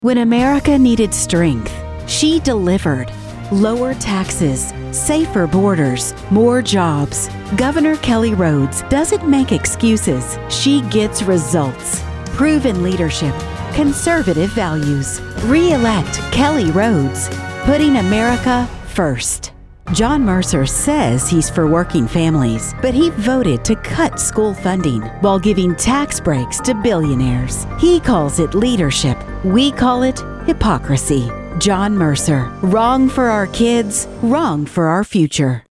Female Voice Over, Dan Wachs Talent Agency.
Female Republican Voices
Variety of great voice actors with pro home studios and Source Connect.